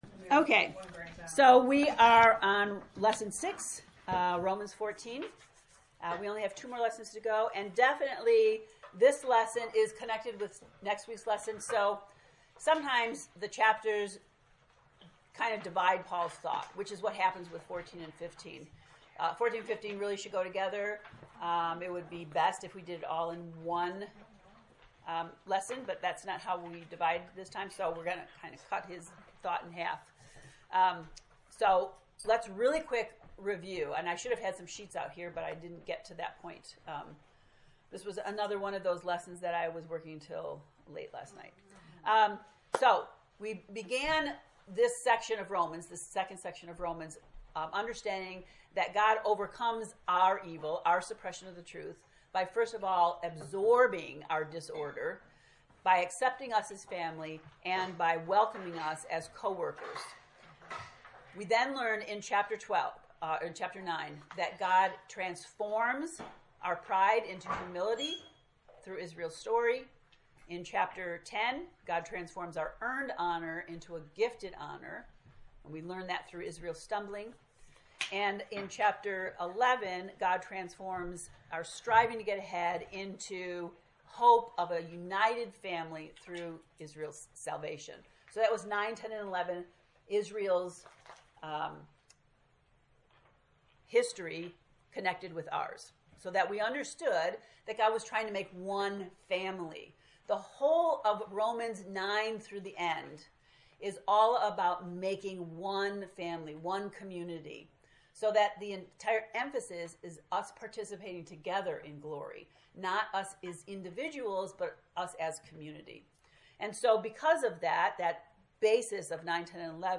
To listen to the lesson 6 lecture, ” Maturing the Community,” click below: